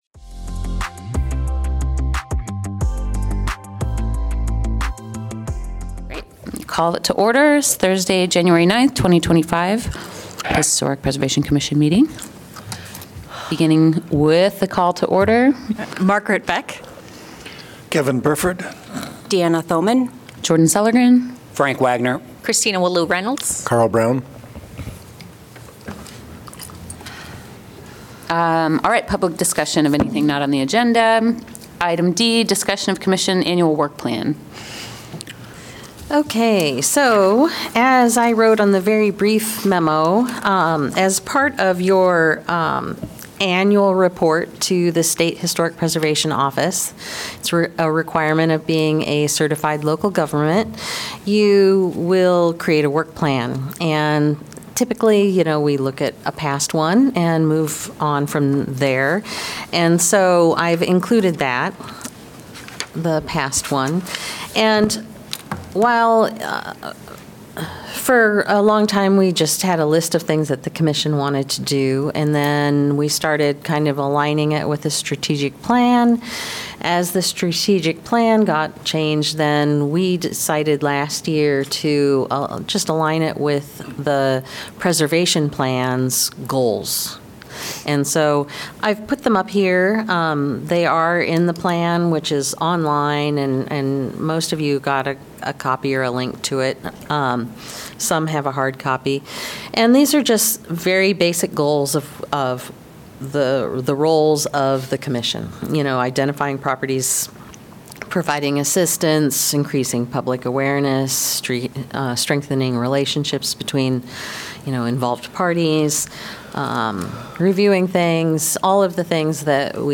Regular meeting of the Iowa City Historic Preservation Commission.